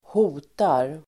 Uttal: [²h'o:tar]